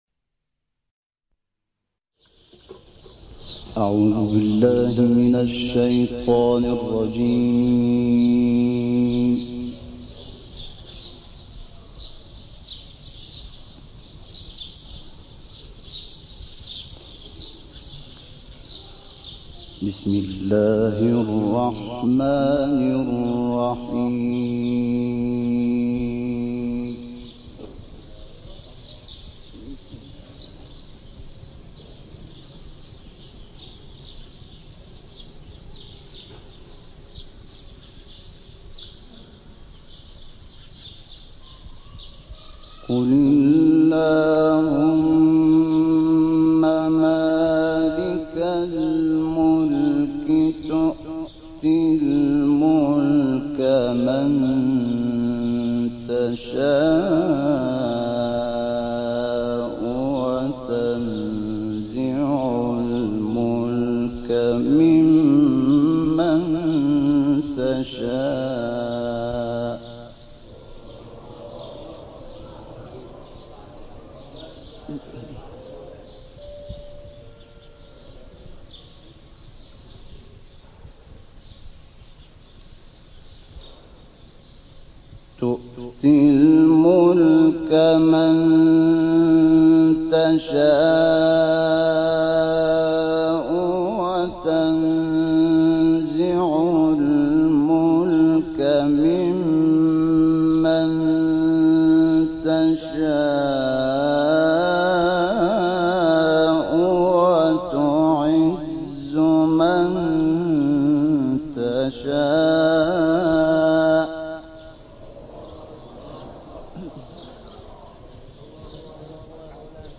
تلاوت سوره آل‌عمران با صدای مرحوم شحات محمد انور+ دانلود